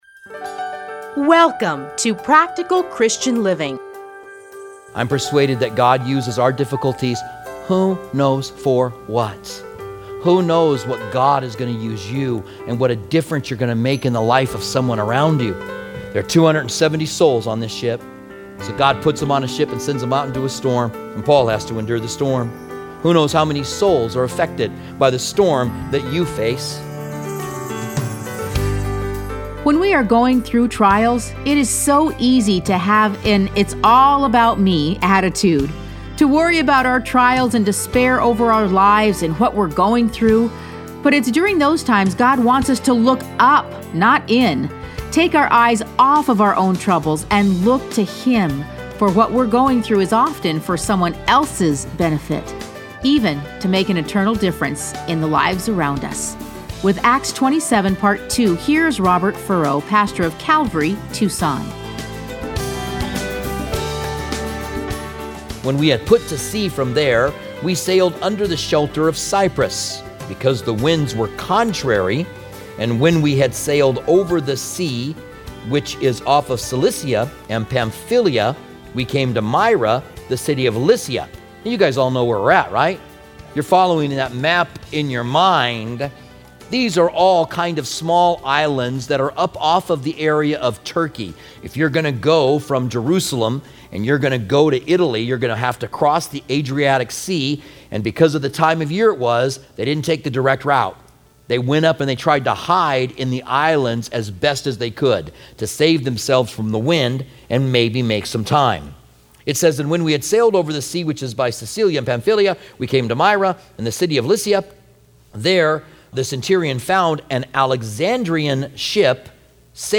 Listen to a teaching from Acts 27.